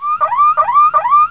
ALARM3.WAV